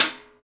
PRC STICK0OR.wav